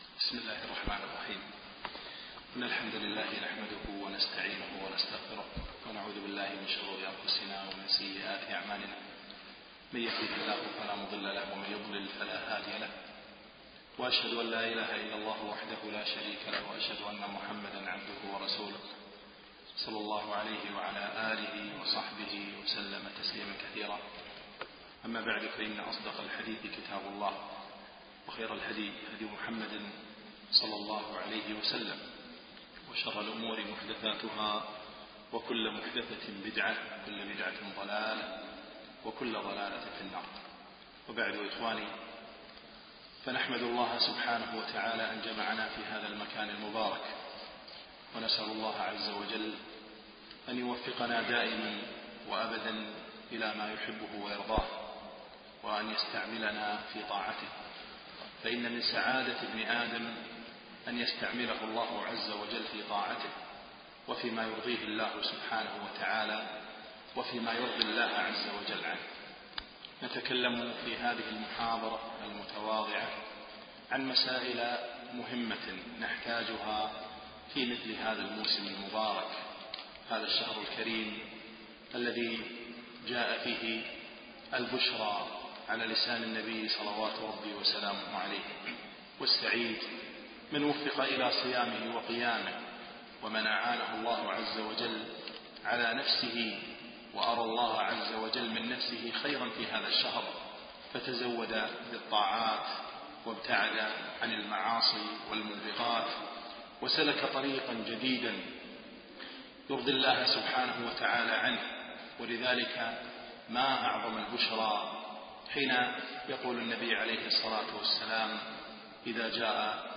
من دروس الشيخ في دولة الإمارات